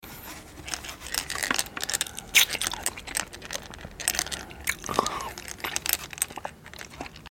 ASMR Lollipop Eating & Shaking Sound Effects Free Download